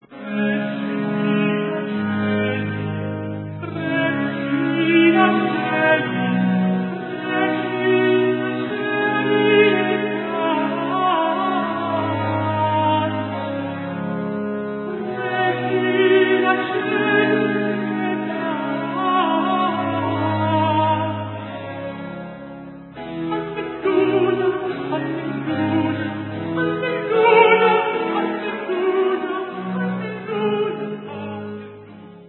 Kirchenmusik (19/28)
Barock/Mittelbarock
contre-ténor